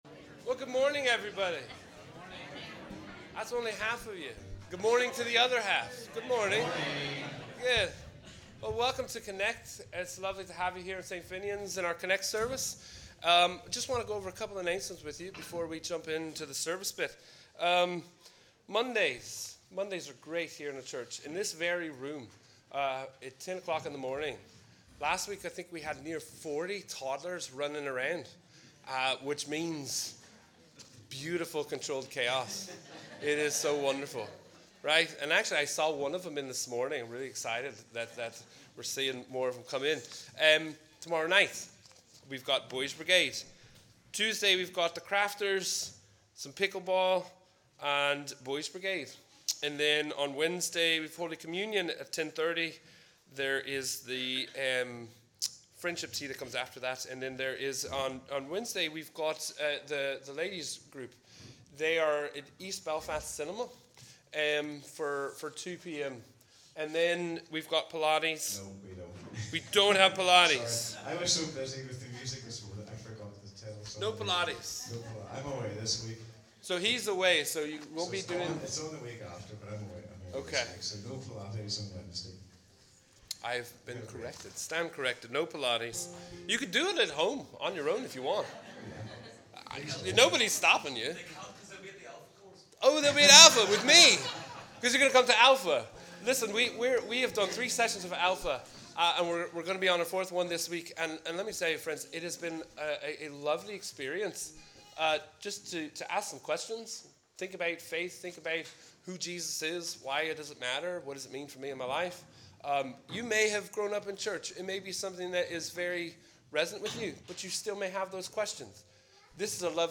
We warmly welcome you to our CONNEC+ service as we worship together on the 16th Sunday after Trinity.